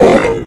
zombief_hit.ogg